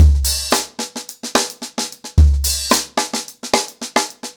HarlemBrother-110BPM.31.wav